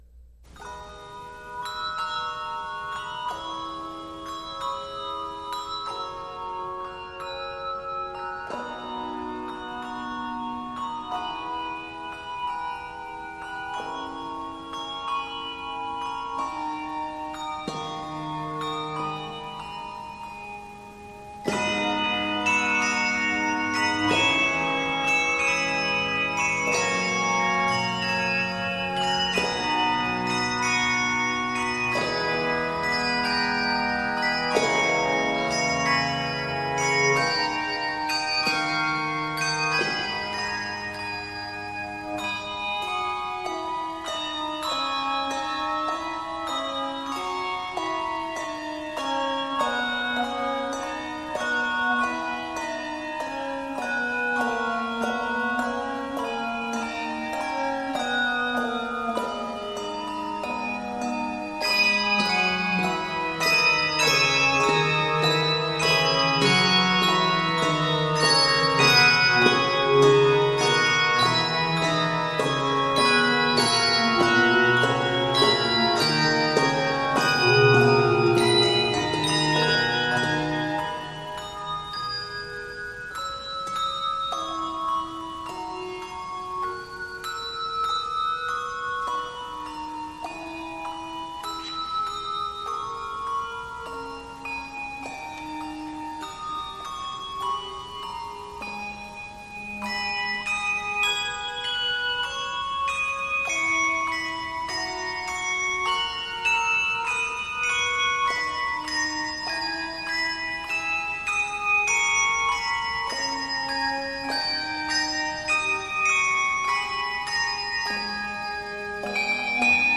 Octaves: 5-6